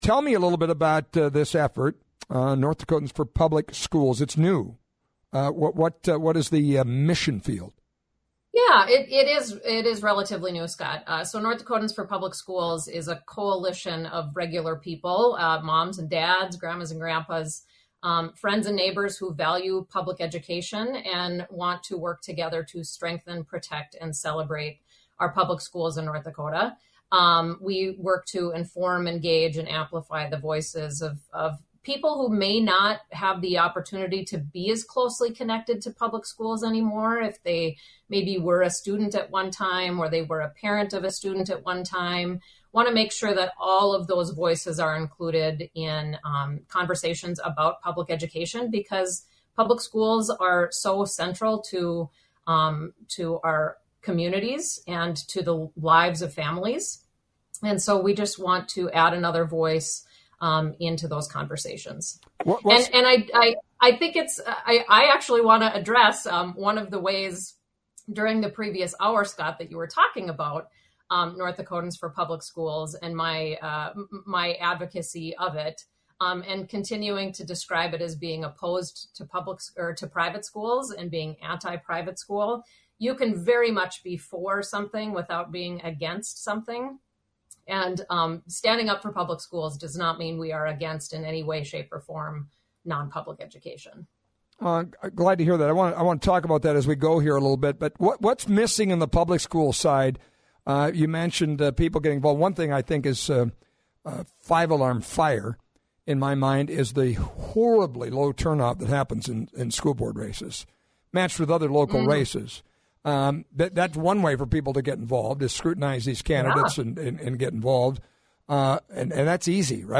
conversation with Erin Oban